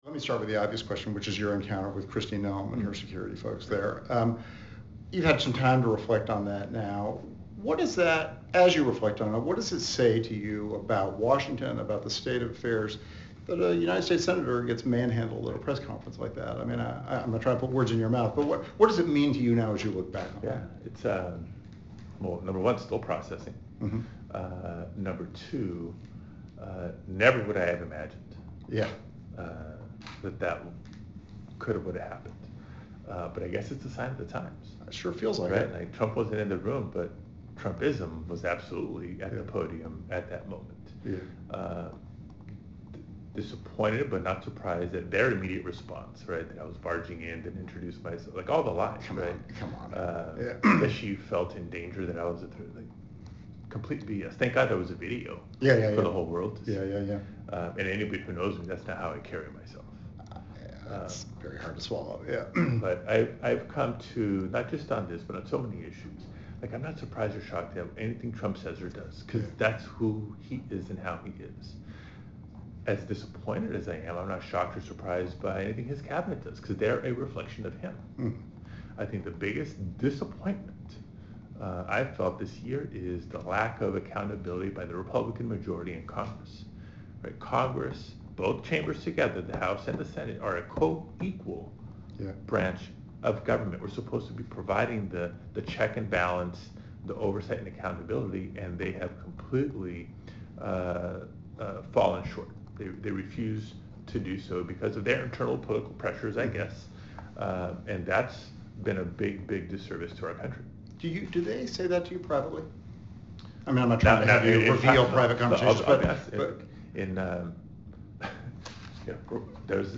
conversation, discussing the senator's manhandling by Noem's security forces this summer